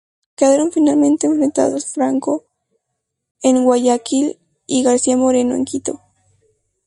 Read more Quito (the capital city of Ecuador) Frequency C1 Hyphenated as Qui‧to Pronounced as (IPA) /ˈkito/ Etymology Named after the Quitu tribe.